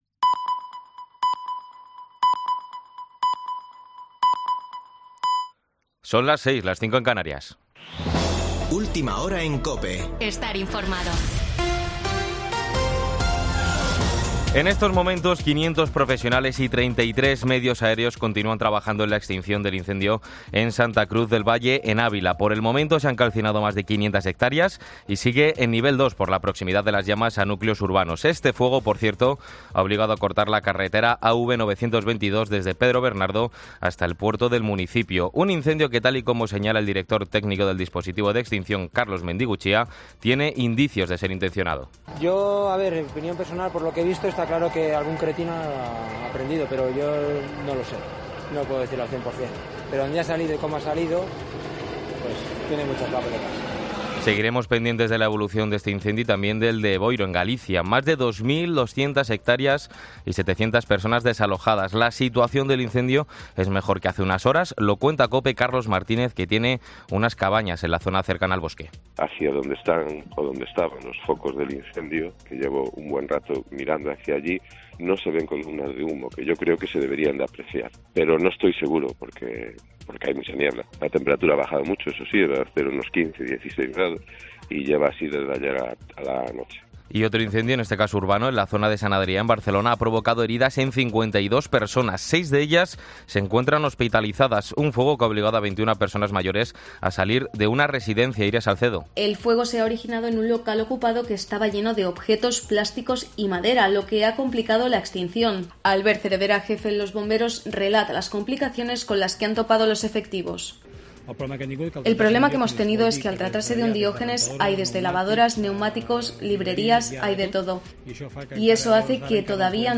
Boletín de noticias de COPE del 7 de agosto de 2022 a las 18.00 horas